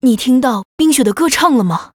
文件 文件历史 文件用途 全域文件用途 Yoshua_amb_02.ogg （Ogg Vorbis声音文件，长度1.9秒，104 kbps，文件大小：25 KB） 文件说明 源地址:游戏语音解包 文件历史 点击某个日期/时间查看对应时刻的文件。 日期/时间 缩略图 大小 用户 备注 当前 2019年1月20日 (日) 04:26 1.9秒 （25 KB） 地下城与勇士  （ 留言 | 贡献 ） 分类:寒冰之休亚 分类:地下城与勇士 源地址:游戏语音解包 您不可以覆盖此文件。